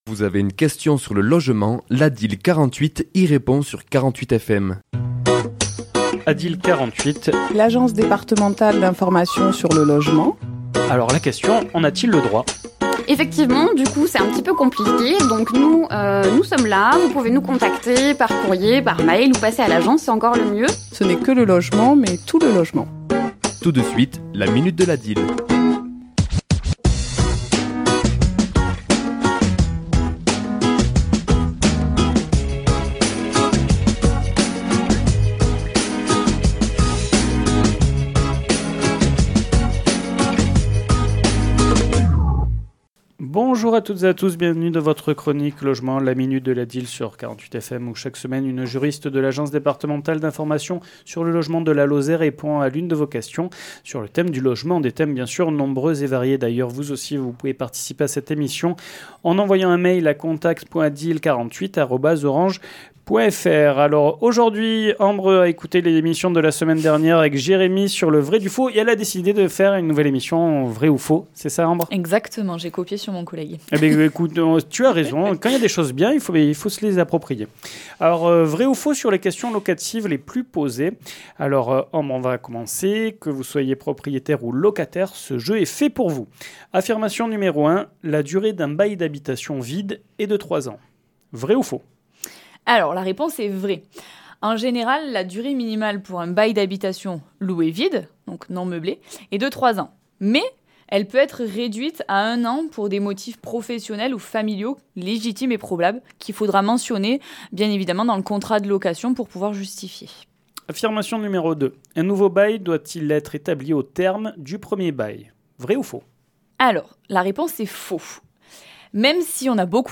Chronique diffusée le mardi 26 novembre à 11h et 17h10